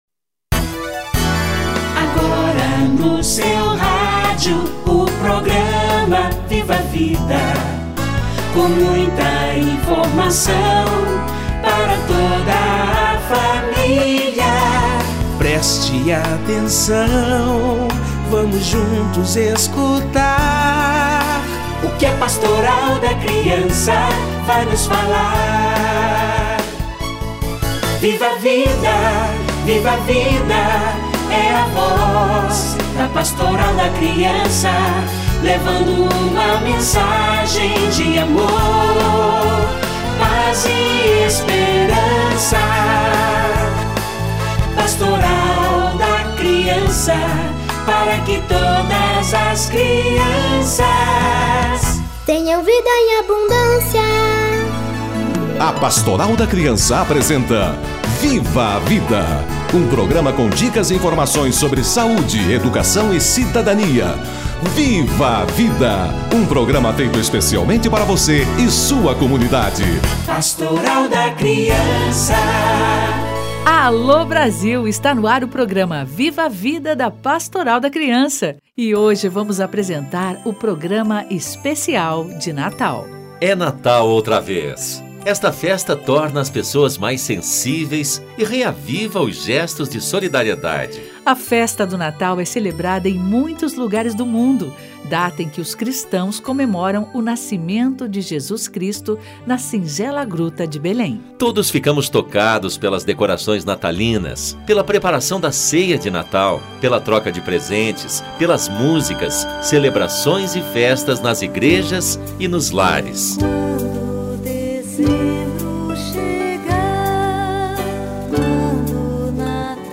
Natal - Entrevista